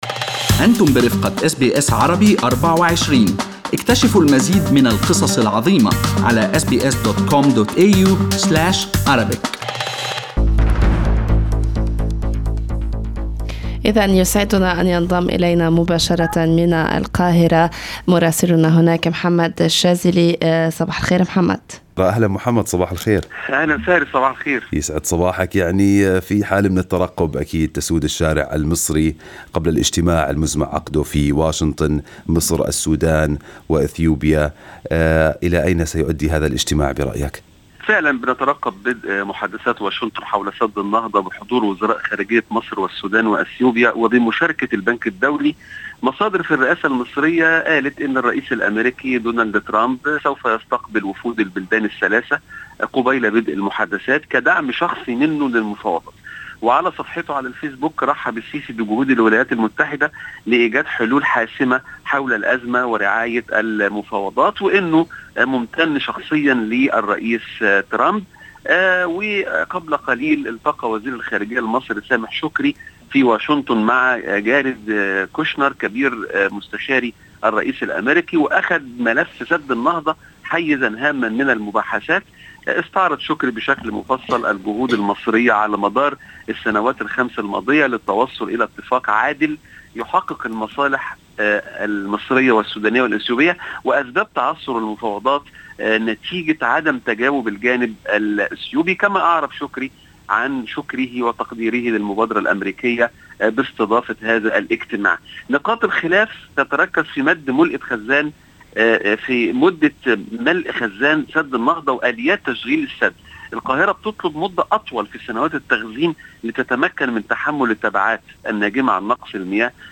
Listen to the full report from our correspondent in Cairo in Arabic above